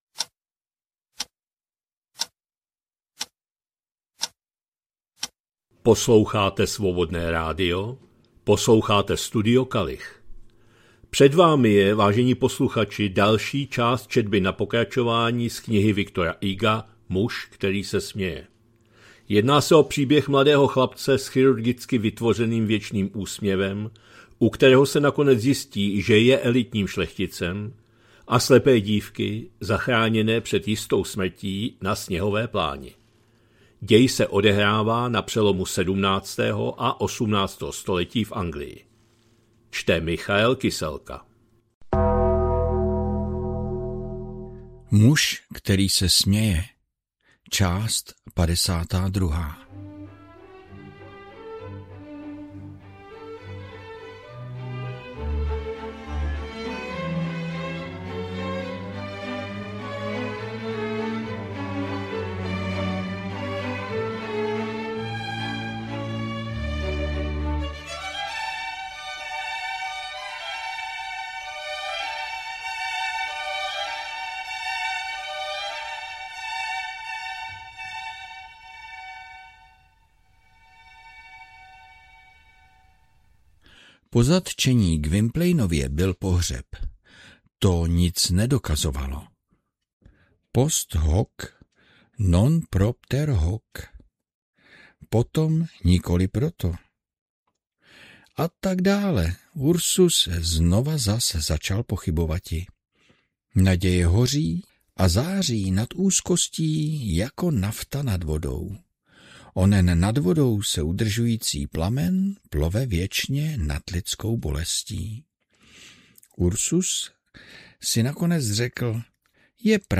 2026-03-13 – Studio Kalich – Muž který se směje, V. Hugo, část 52., četba na pokračování.